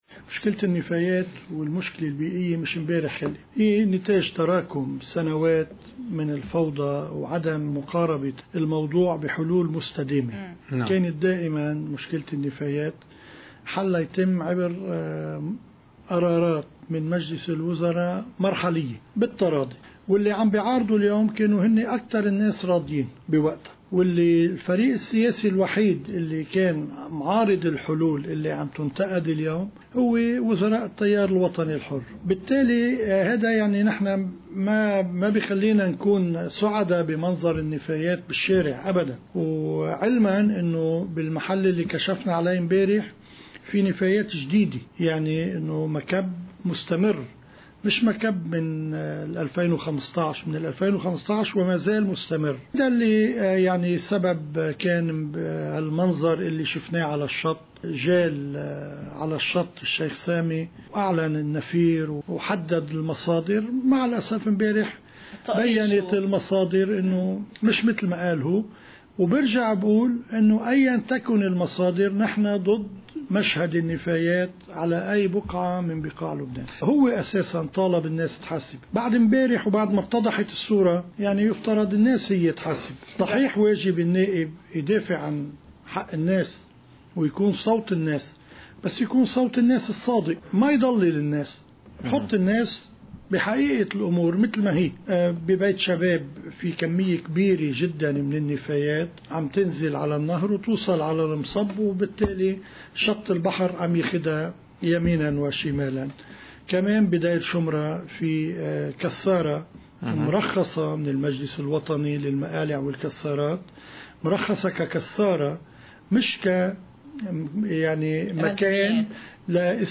مقتطف من حديث وزير البيئة طارق الخطيب ضمن برنامج “أجندة اليوم”: (24 كانون2 – 2018)